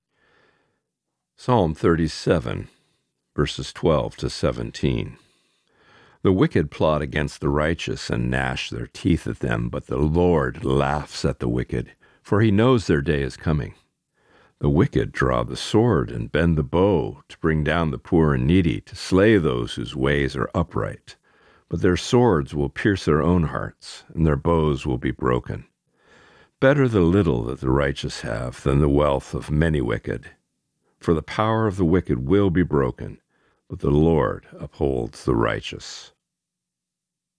Reading: Psalm 37:12-17